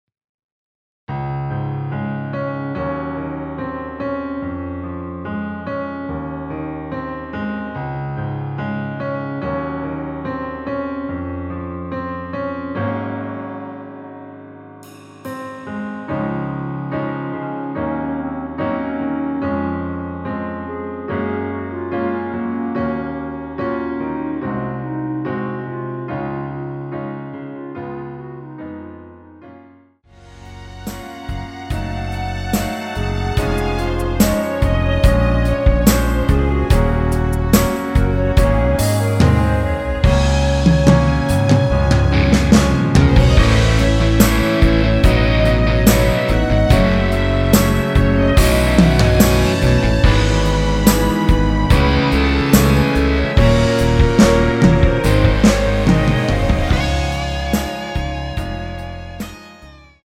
원키에서(-2)내린 멜로디 포함된 MR입니다.(미리듣기 확인)
Db
앞부분30초, 뒷부분30초씩 편집해서 올려 드리고 있습니다.
중간에 음이 끈어지고 다시 나오는 이유는